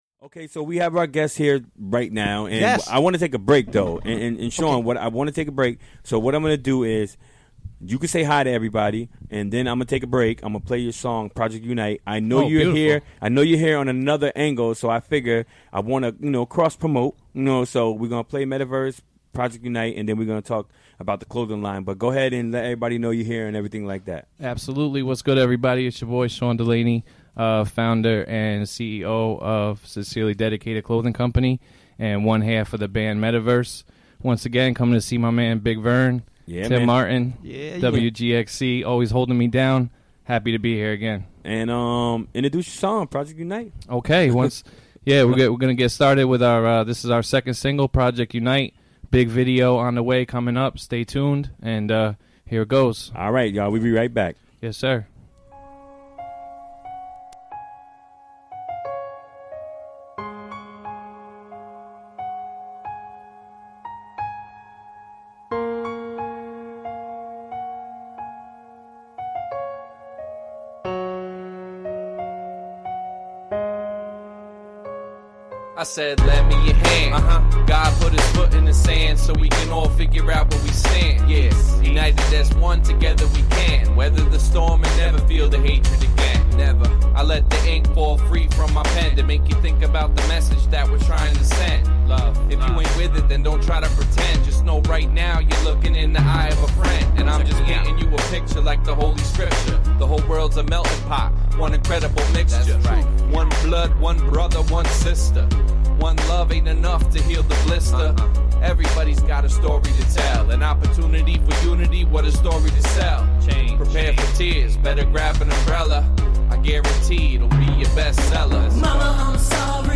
Recorded during the WGXC Afternoon Show Wednesday, November 1, 2017.